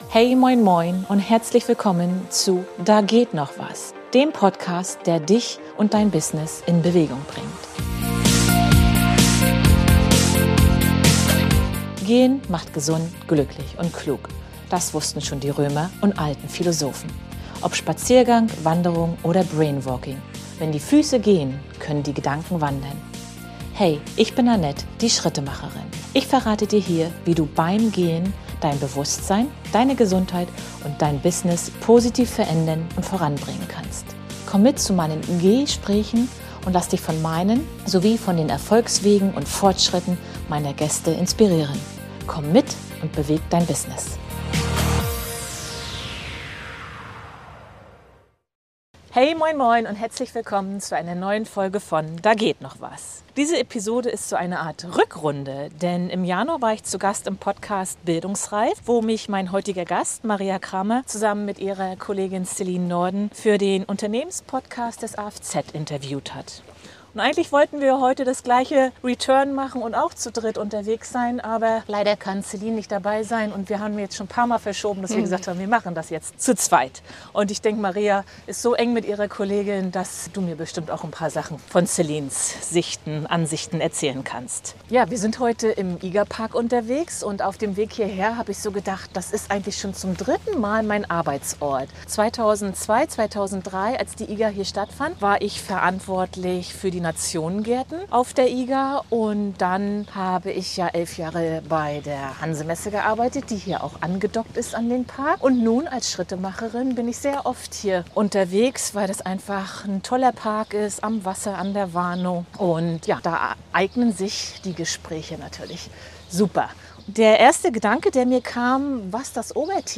GEHspräch